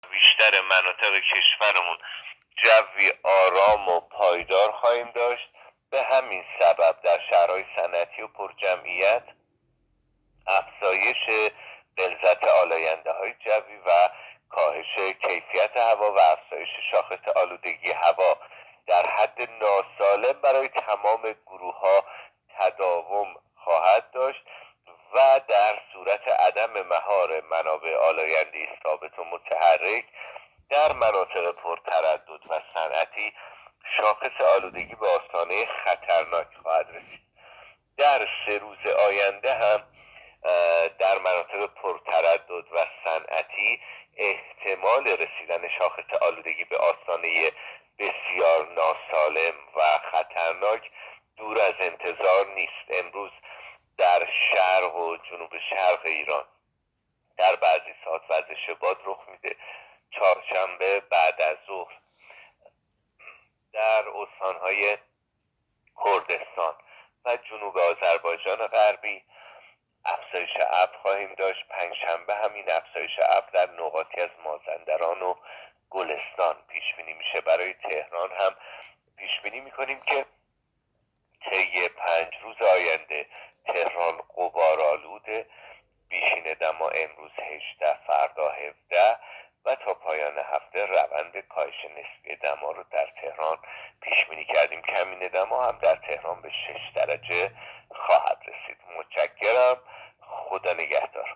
گزارش رادیو اینترنتی پایگاه‌ خبری از آخرین وضعیت آب‌وهوای ۳ آذر؛